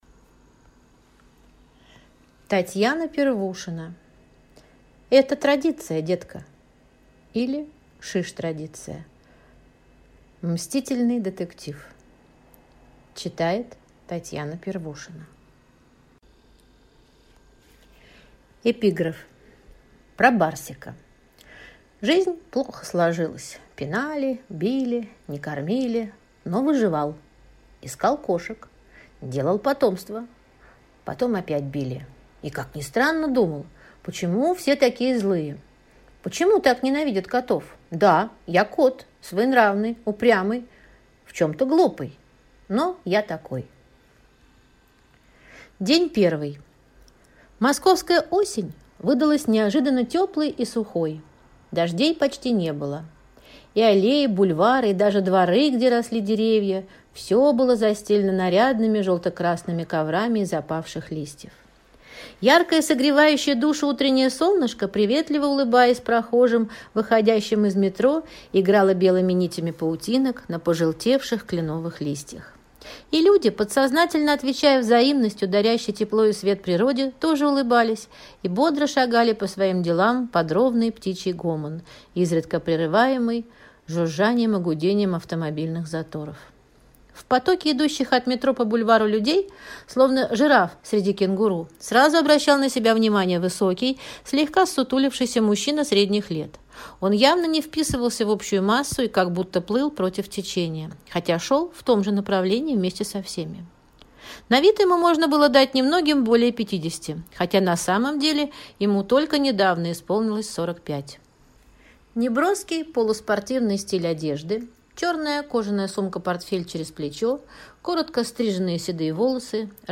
Аудиокнига Это традиция, детка!